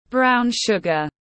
Đường nâu tiếng anh gọi là brown sugar, phiên âm tiếng anh đọc là /ˌbraʊn ˈʃʊɡ.ər/
Brown sugar /ˌbraʊn ˈʃʊɡ.ər/